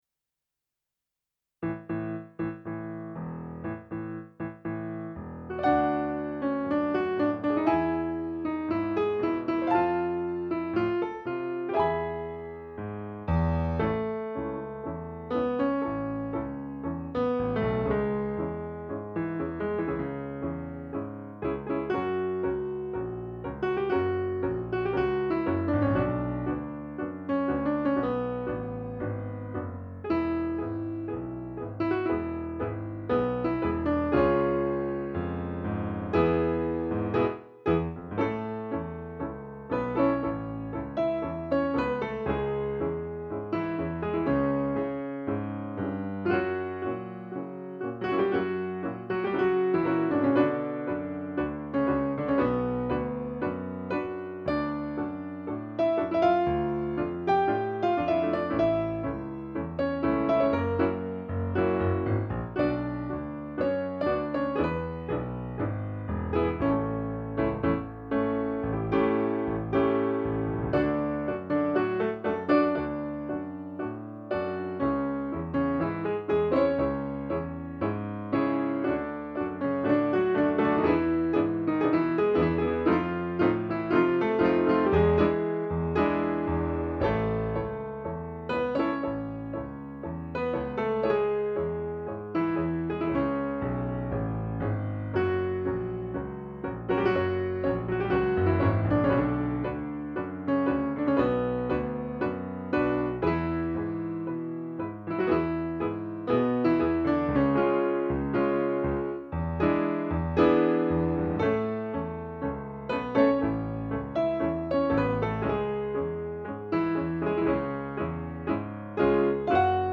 TANGO
Tango Piano